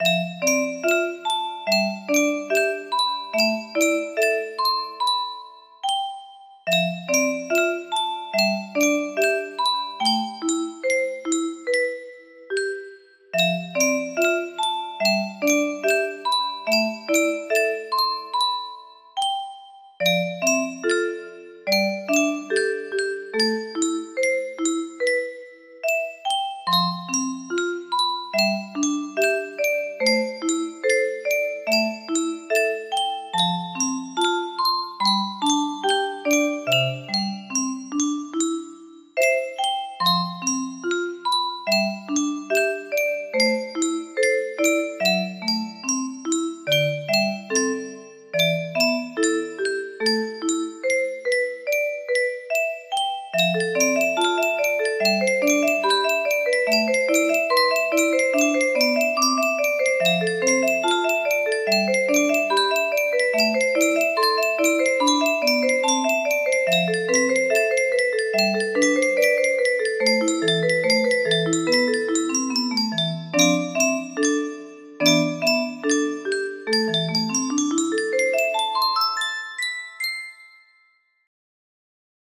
BPM 72
(intro twice-chorus-piano solo-ending)